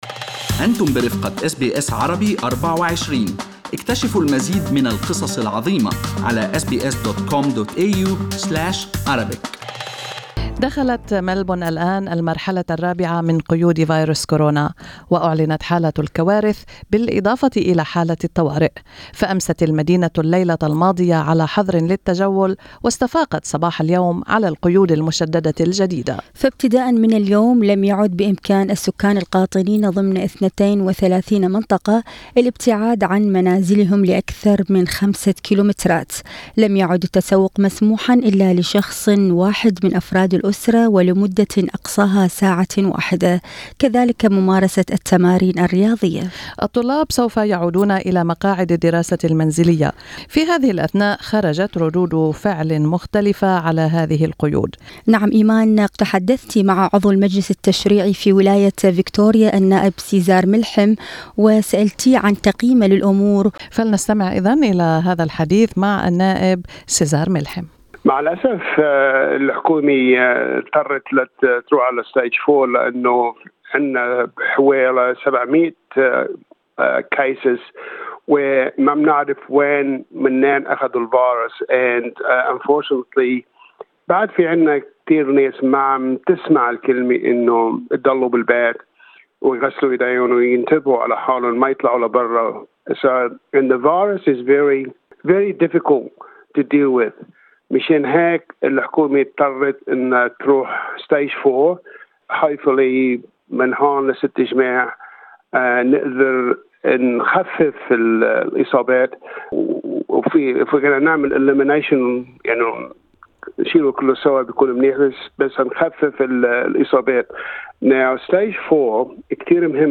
وفي حديث مع SBS Arabic24 ناشد عضو المجلس التشريعي في ولاية فيكتوريا سيزار ملحم سكان ولاية فيكتوريا بضرورة الاستماع إلى التعليمات، وقال إن الأسابيع القادمة سوف تكون صعبة ومؤلمة لأن مرحلة الإغلاق الجديدة ستستمر لفترة ستة أسابيع.